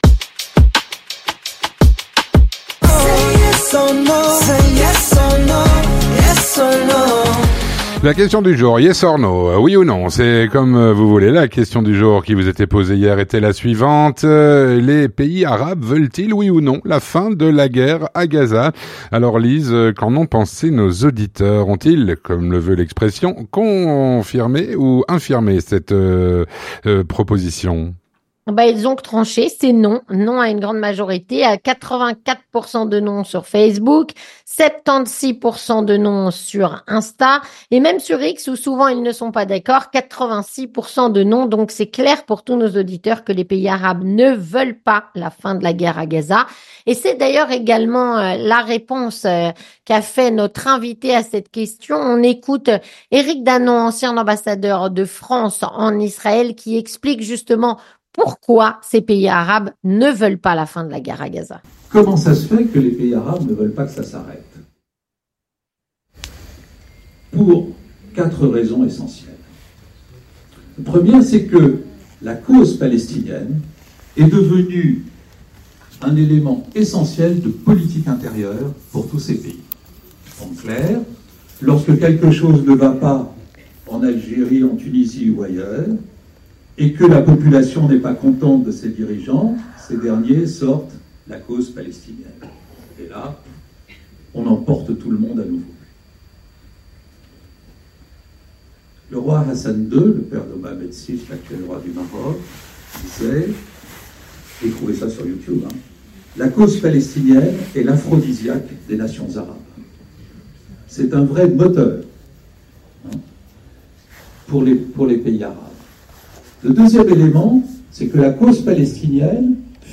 Eric Danon, ancien ambassadeur de France en Israël, répond à "La Question Du Jour".